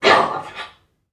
NPC_Creatures_Vocalisations_Robothead [12].wav